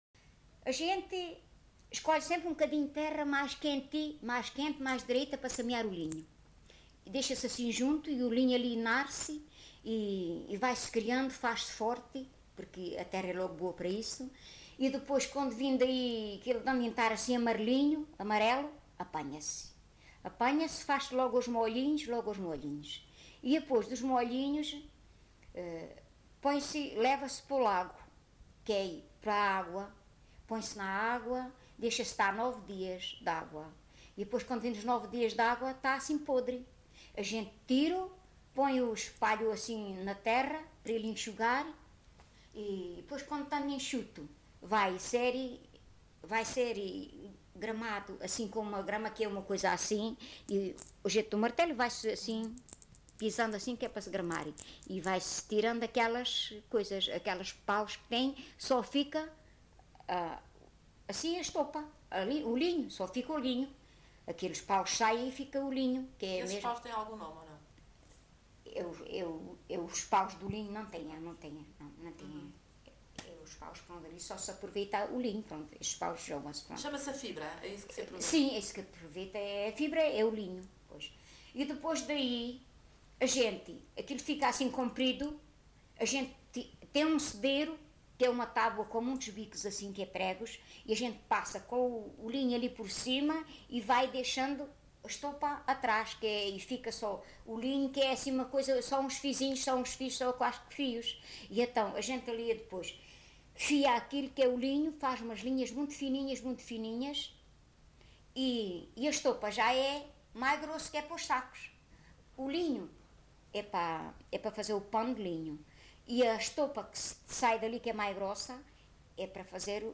LocalidadeVale Chaim de Baixo (Odemira, Beja)